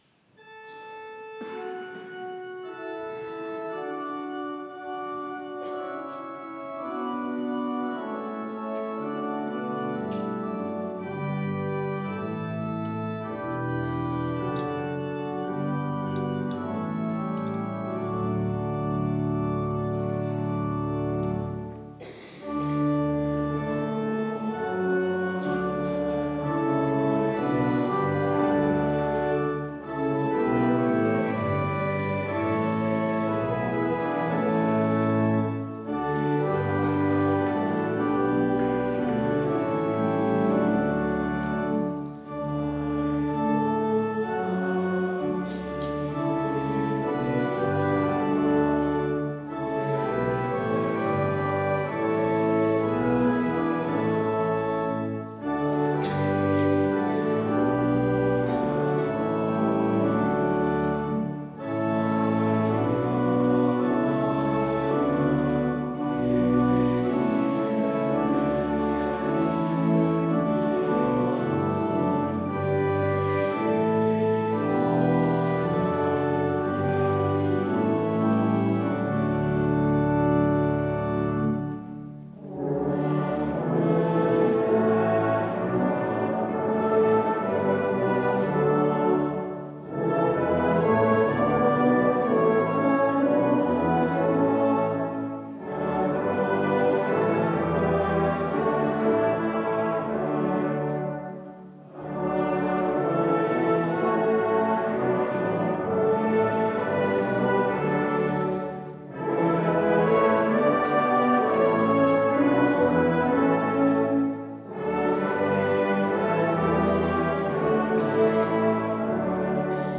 Wie schön leuchtet der Morgenstern... (LG 78,5-7) Ev.-Luth. St. Johannesgemeinde Zwickau-Planitz
Audiomitschnitt unseres Gottesdienstes zum Epipaniasfest 2026.